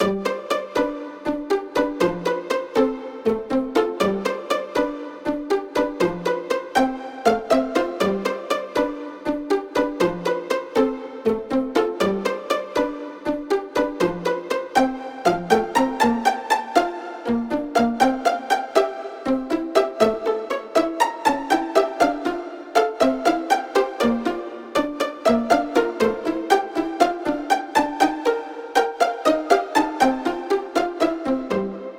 ピッツィカートを使用したいつもの日常のBGM素材です。